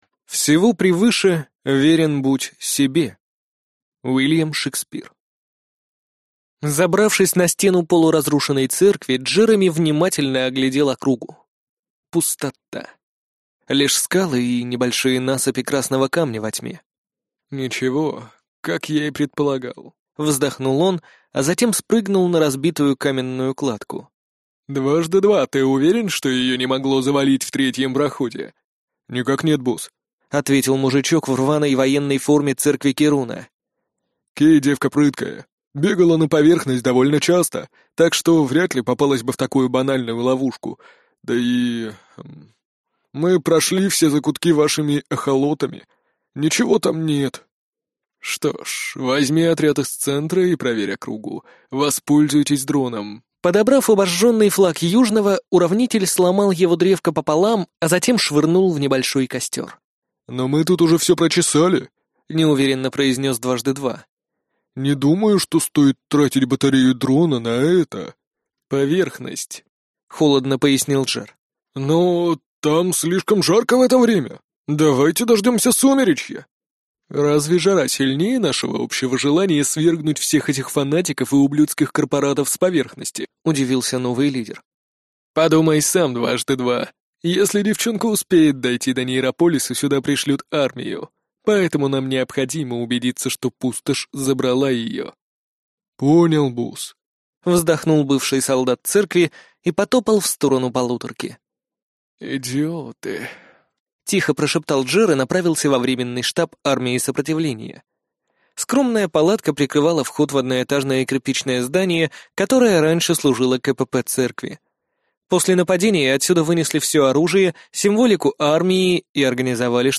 Аудиокнига Восход. Том 1 | Библиотека аудиокниг